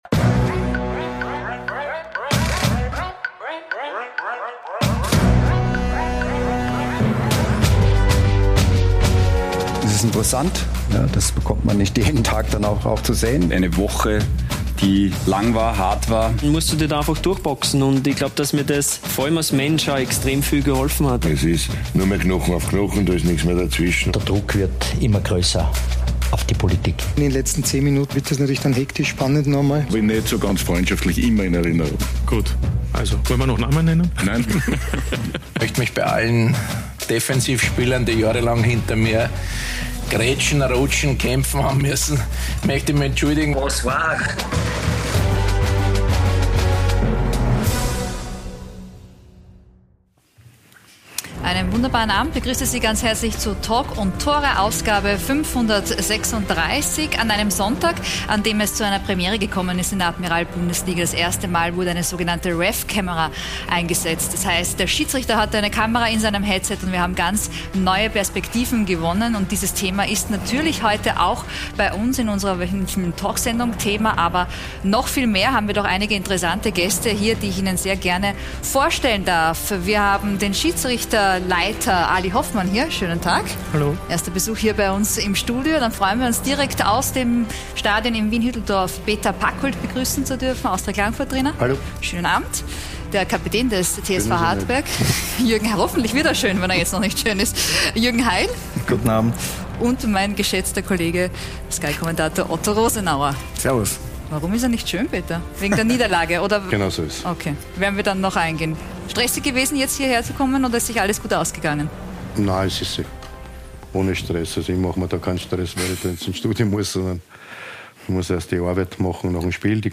„Talk und Tore“ ist die erste und einzige Fußballtalksendung in Österreich. Wir liefern neue Blickwinkel, Meinungen und Hintergründe zu den aktuellen Themen im österreichischen Fußball und diskutieren mit kompetenten Gästen die aktuellen Entwicklungen.